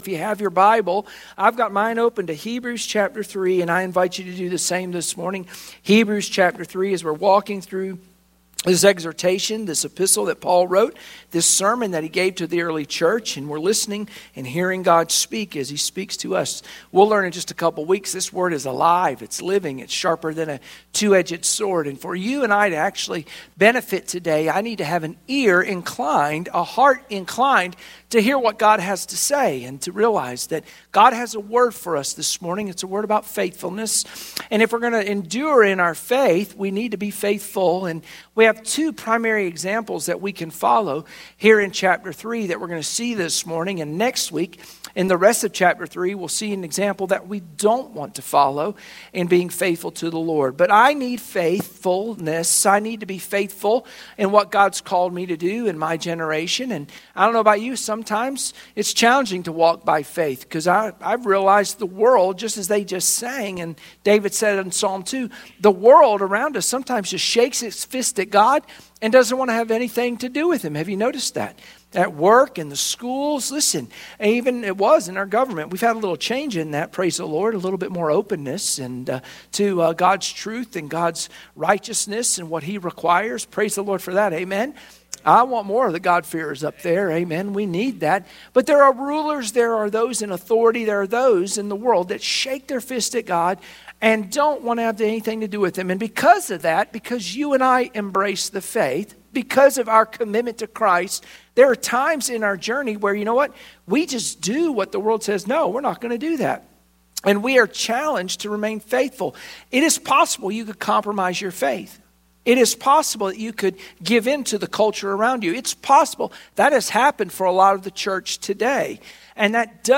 Sunday Morning Worship Passage: Hebrews 3:1-2 Service Type: Sunday Morning Worship Share this